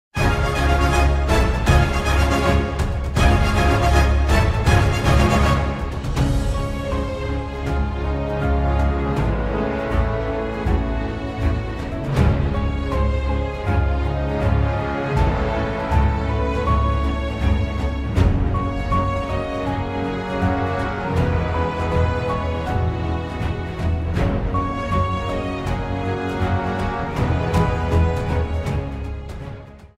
Trimmed to 30 seconds, with a fade out effect